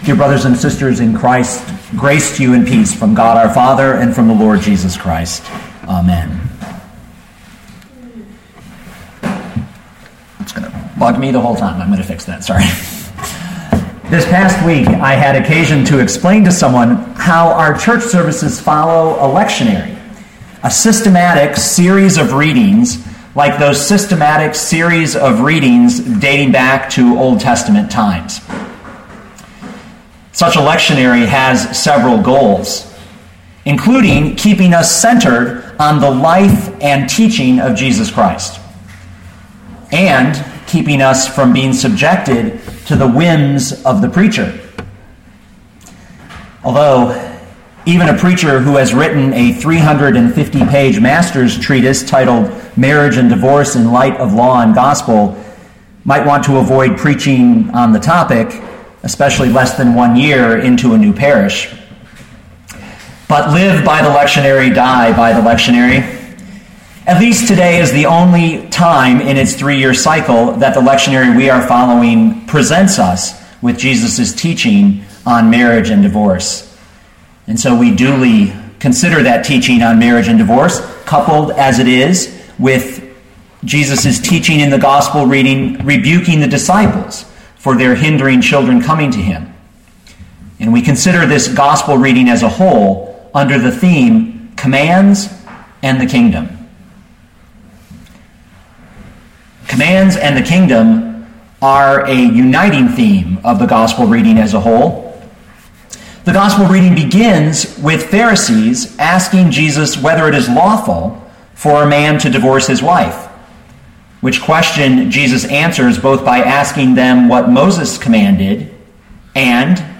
2012 Mark 10:2-16 Listen to the sermon with the player below, or, download the audio.